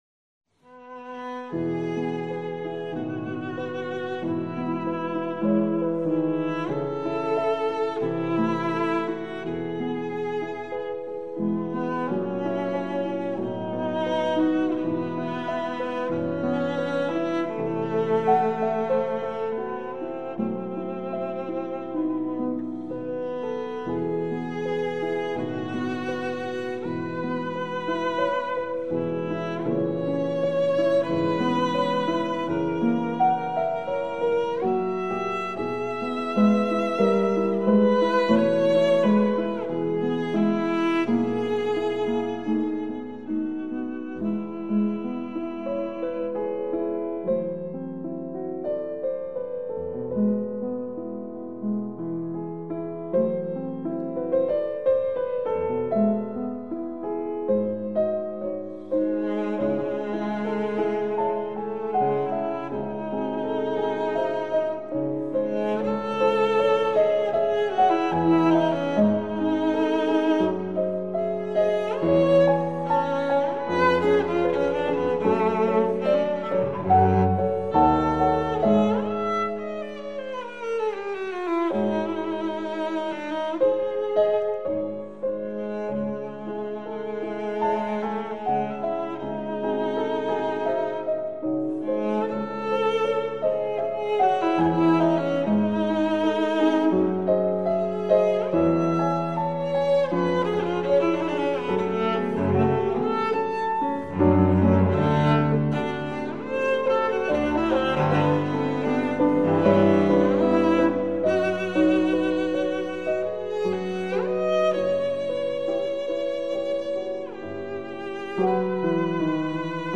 The pieces are inthe Romantic style.